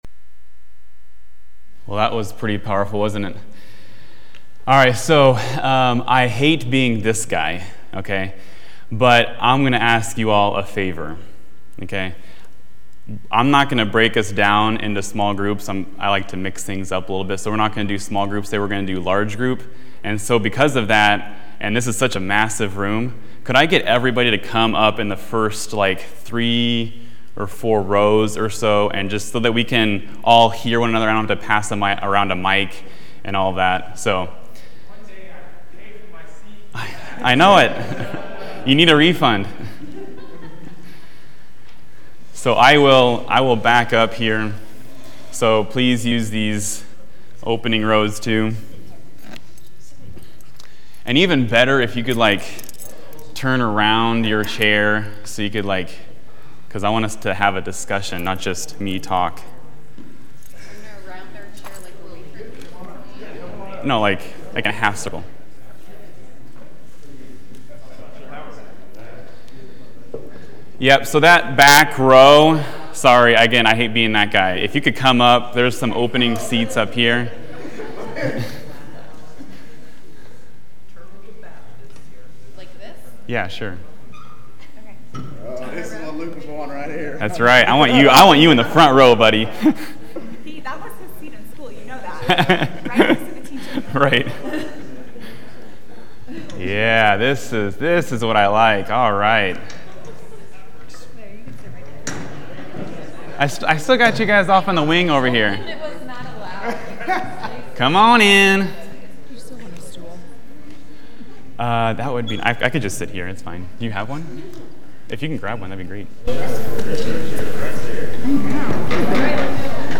In this adult Sunday School class, we think about how God’s heart of mercy towards sinners and sufferers was revealed in the Old Testament.